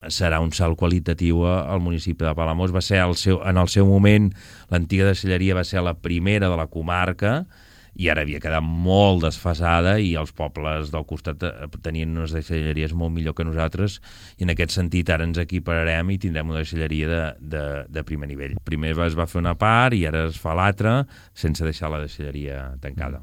El regidor de Medi Ambient, Xavier Lloveras, n’ha parlat al respecte a Ràdio Palamós.